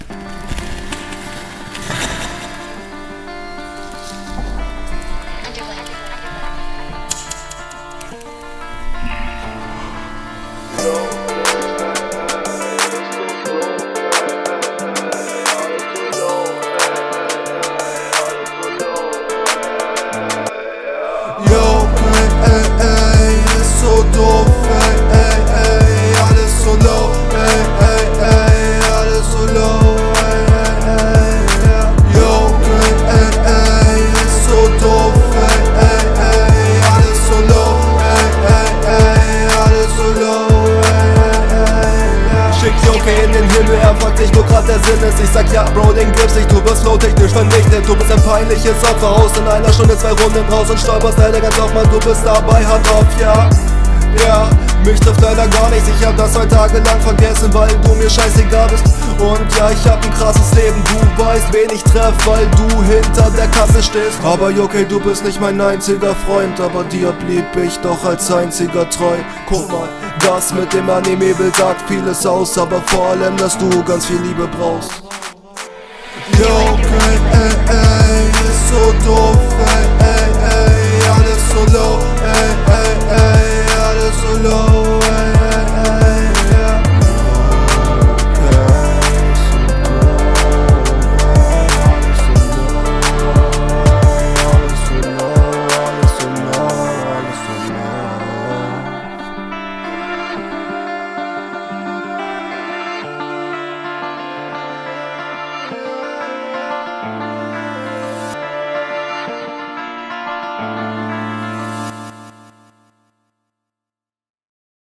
Junge läufst du im Intro durch nen' Ubahntunnel?
Auch der Stimmeinsatz auch besser, …